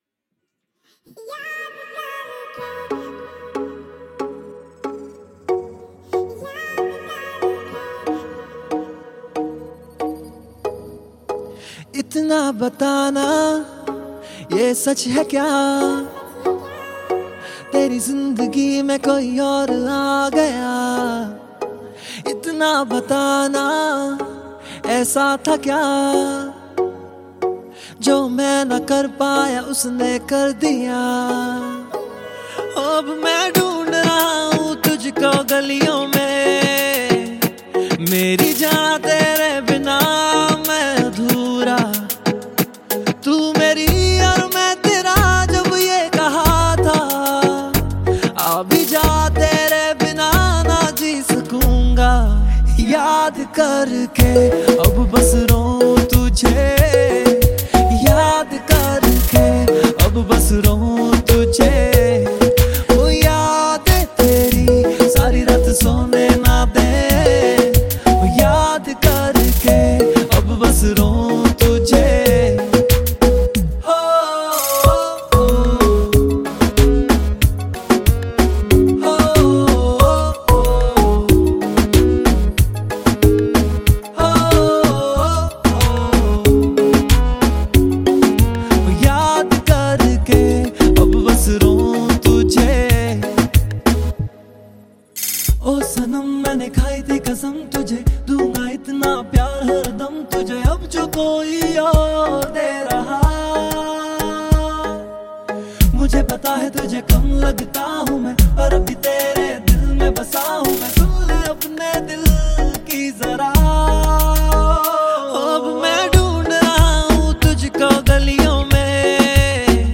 Indian Pop